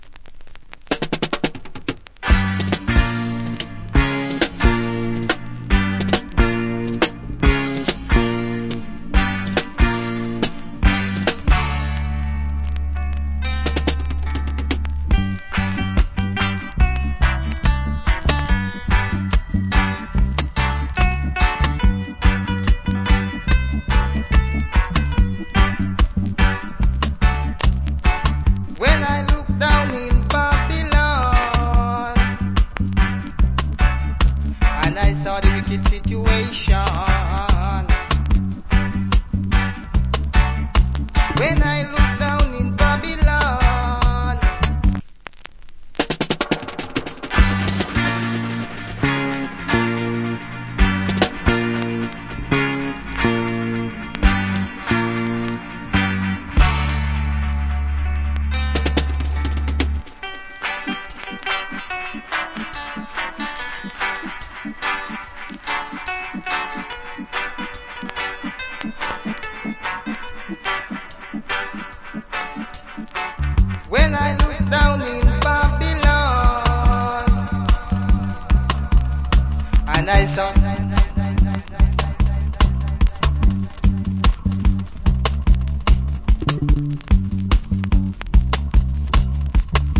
Cool Roots Rock Vocal.